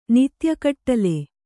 ♪ nitya kaṭṭale